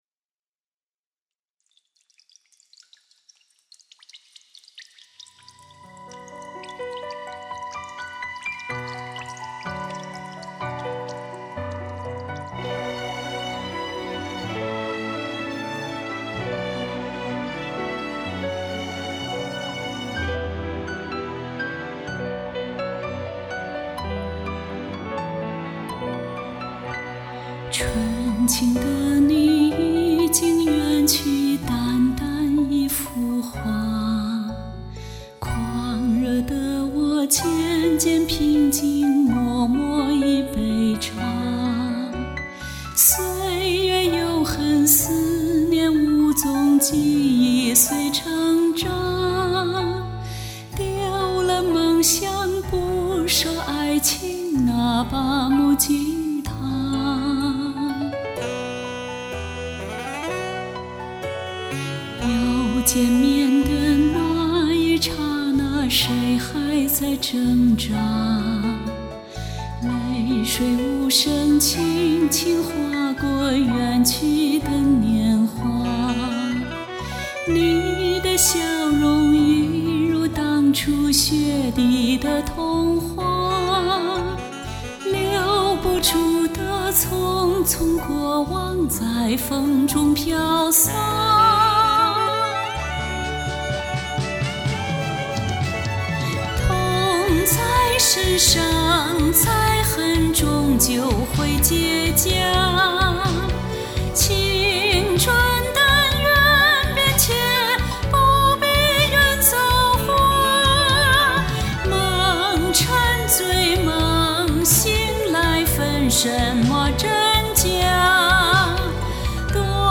唱得俺气短的感觉～～